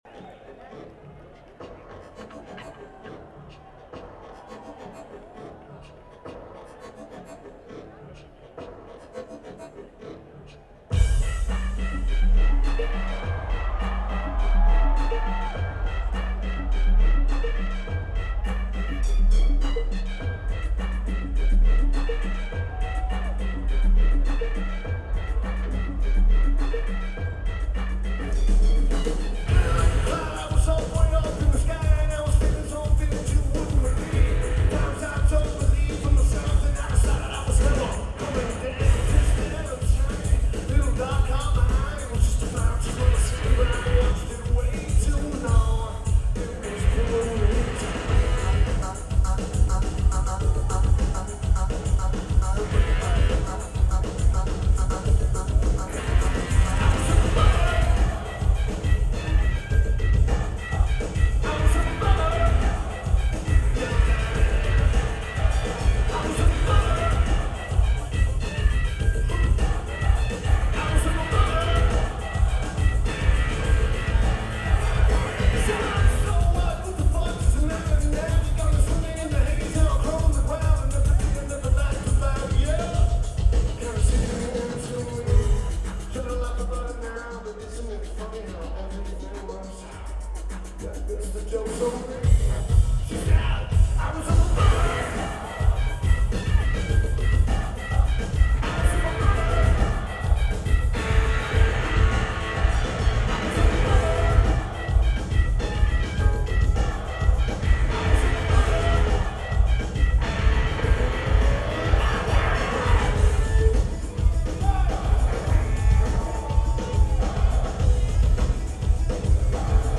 Saratoga Performing Arts Center
Lineage: Audio - AUD (CSB's + Sony PCM-M1)